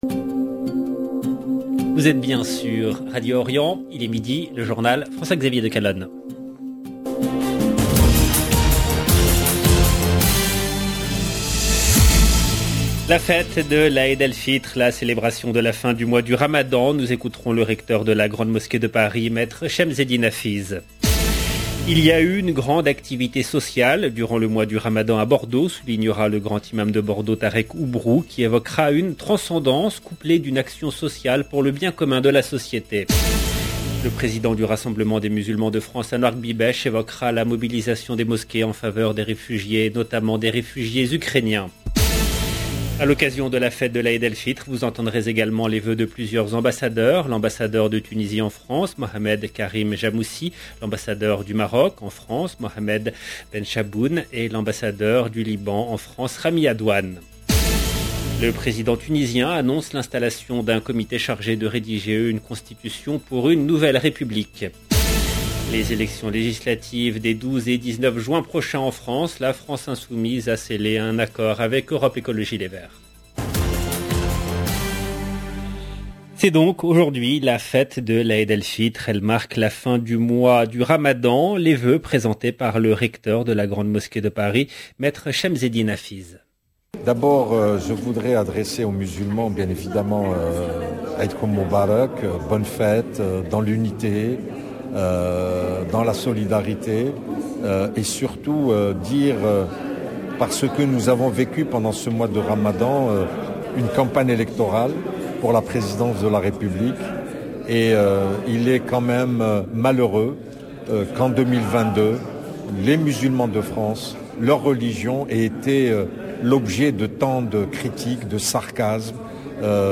LE JOURNAL DE 12H EN LANGUE FRANCAISE DU 2/5/2022